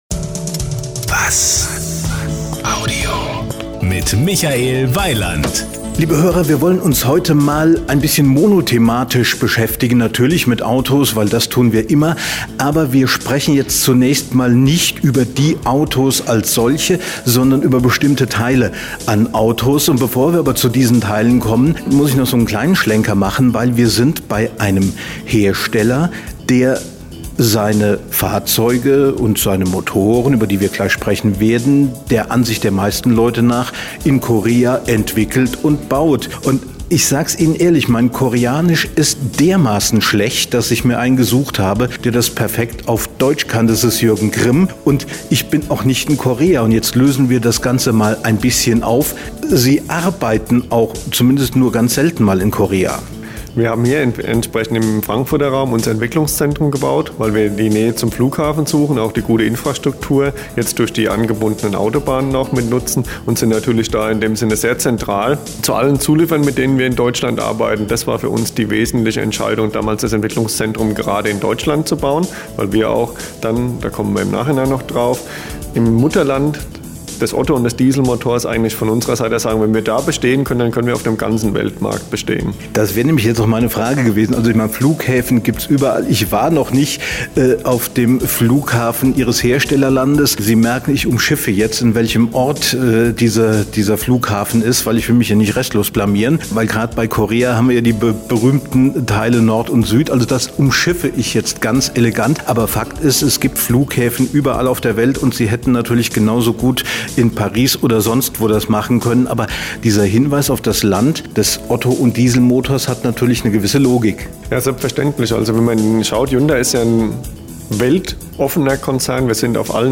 Interviews 2011
Radio- Beiträge und Interviews zu Auto Motor Wirtschaft Gesundheit Computer Software